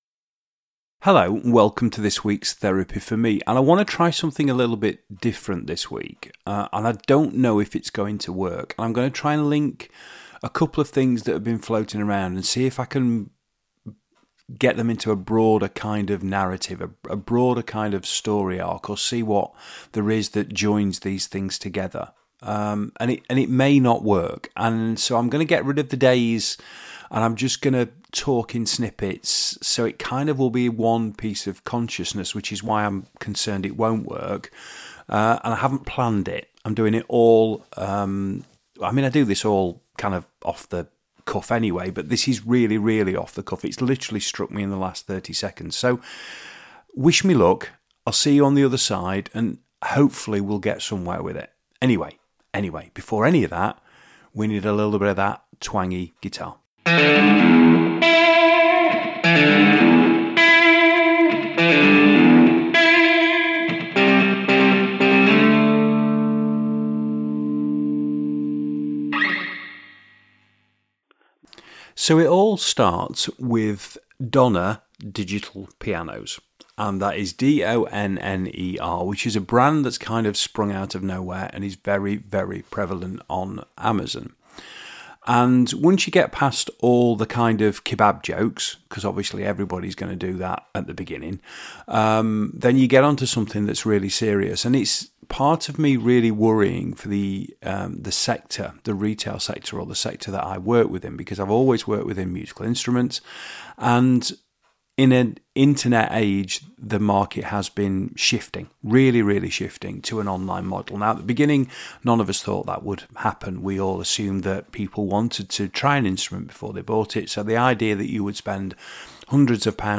It's remains loose in style, fluid in terms of content and raw - it's a one take, press record and see what happens, affair.